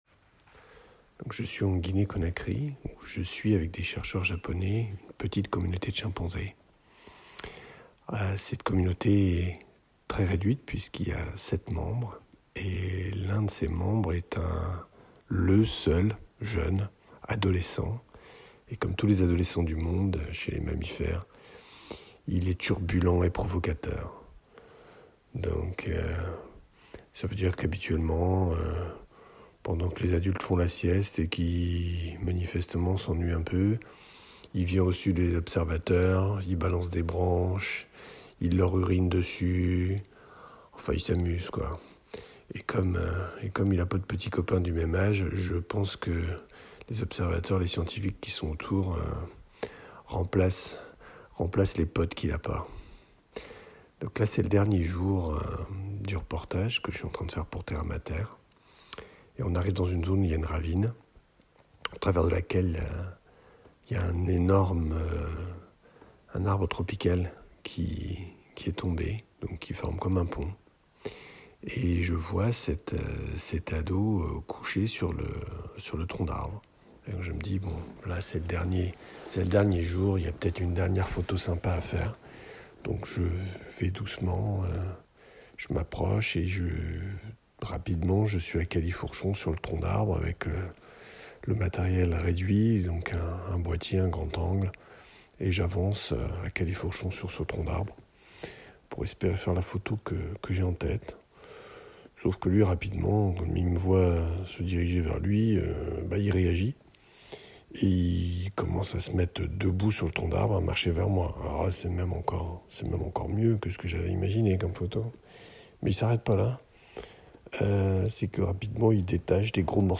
Chimp-ecorce-14mp3.mp3